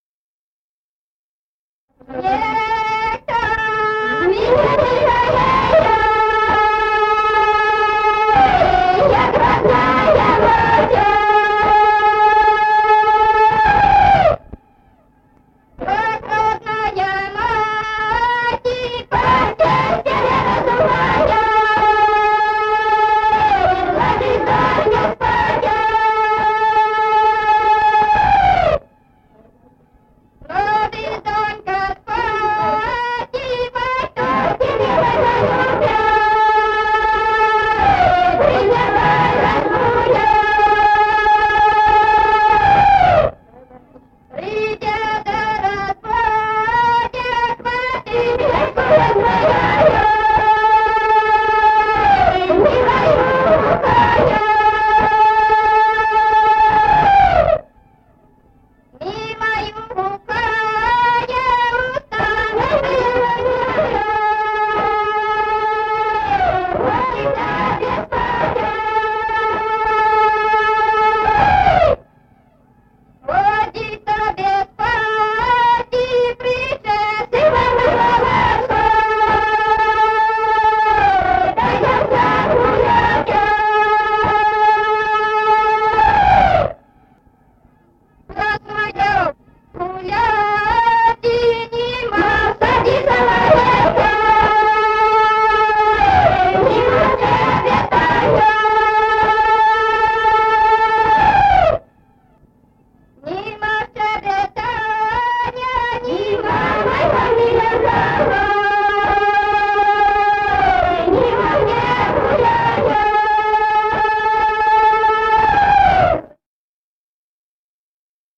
Народные песни Стародубского района «Никто меня не жалея», весняная девичья.
с. Курковичи.